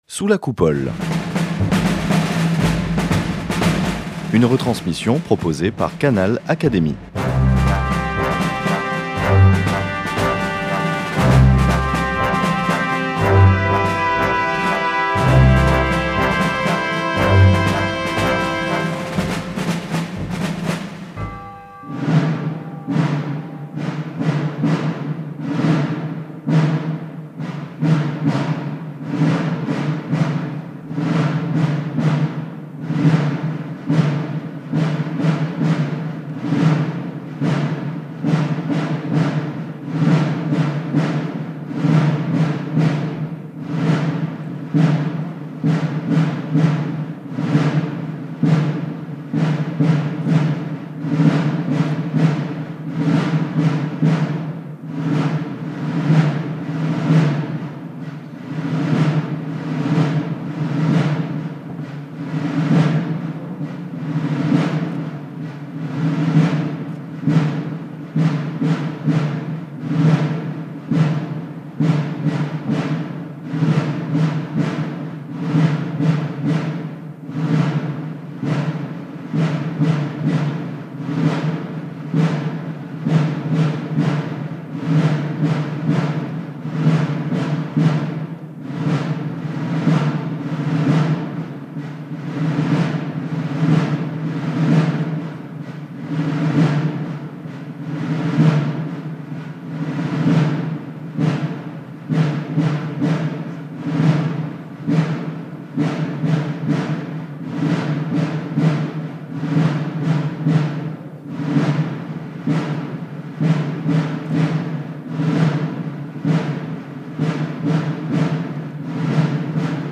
Reprenant les communications prononcées depuis plusieurs mois par de grands témoins à l’invitation de l’Académie des sciences morales et politiques, le président Collomb s’est interrogé sur la place de la France dans un monde en pleine recomposition. Une intervention prémunissant tout à la fois des dangers de l’autocélébration et de ceux du déclinisme.